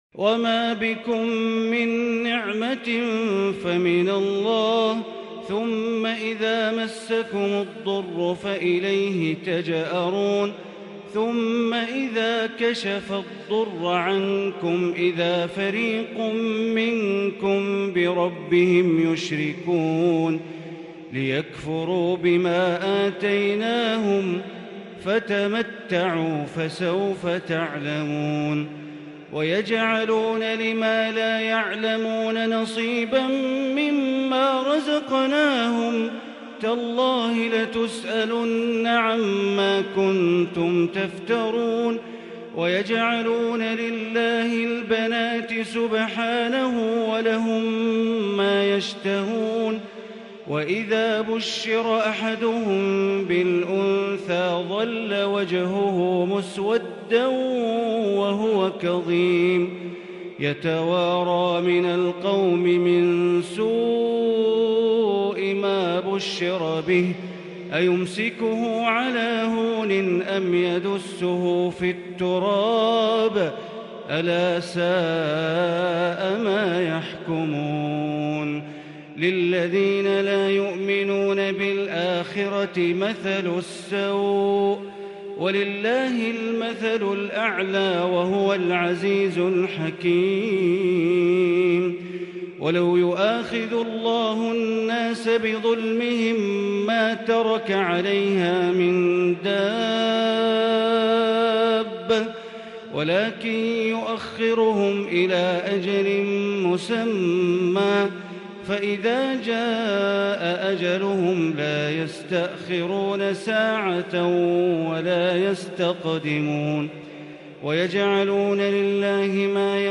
بتلاوة خاشعة مُسترسله يحبر الشيخ من سورة النحل (53-119) ليلة 19 رمضان 1442 > تراويح ١٤٤٢ > التراويح - تلاوات بندر بليلة